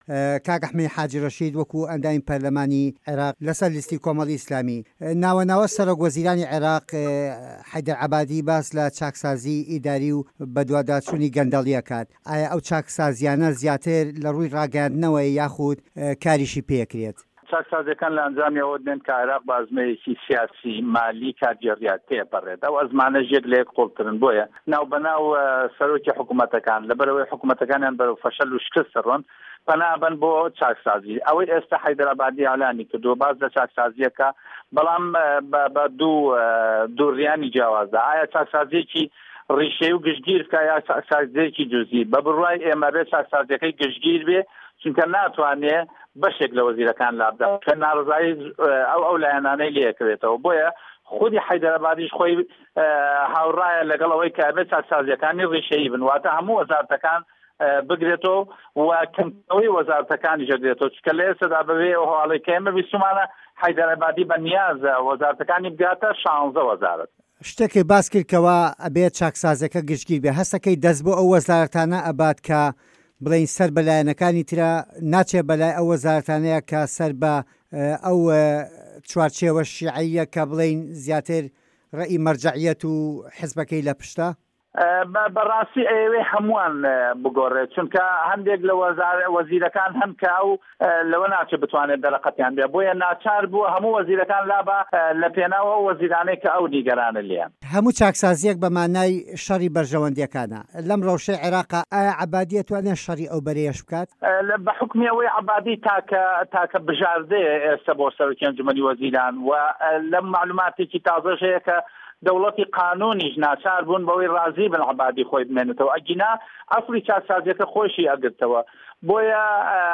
وتووێژ لەگەڵ ئه‌حمه‌دی حاجی ره‌شید